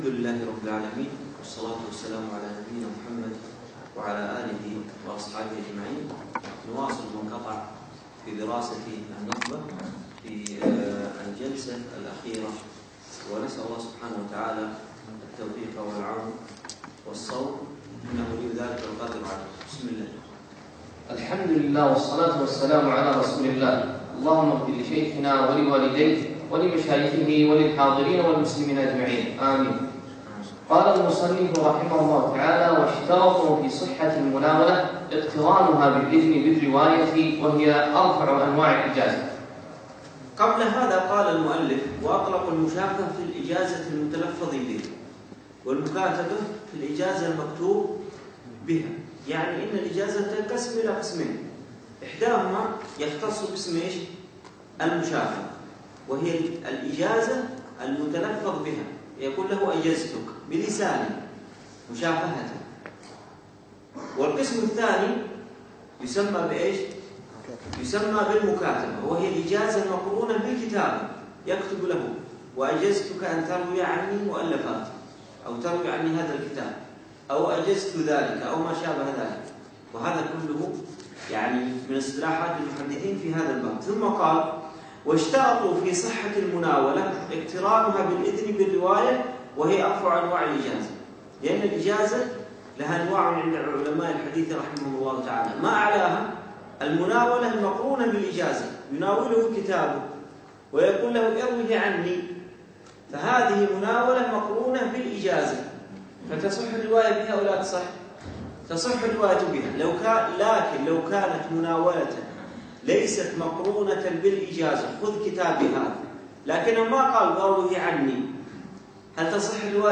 يوم السبت 7 جمادى أول 1438 الموافق 4 2 2017 في مسجد زين العابدين سعد ال عبدالله